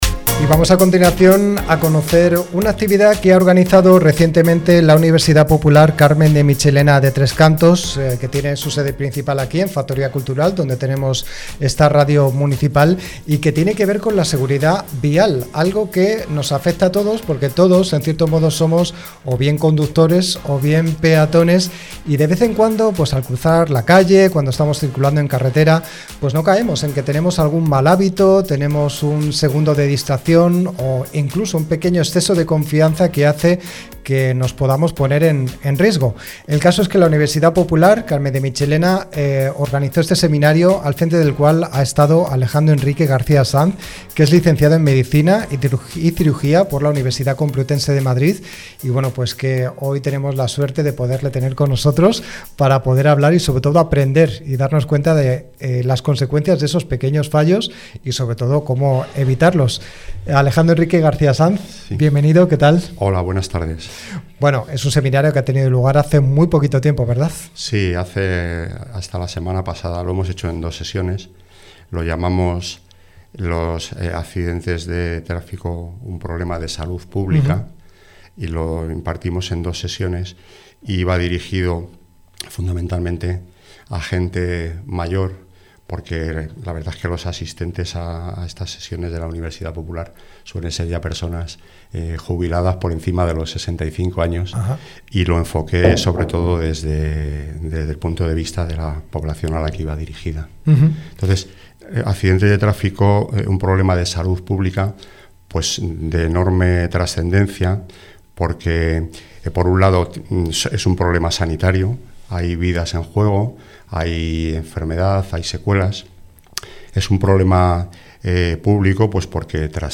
La Hora Dos Punto Dos - Entrevista
También escuchamos el espacio Aliens go home y un reportaje dedicado a los ganadores del certamen de cine corto, con testimonios y protagonistas del talento audiovisual local.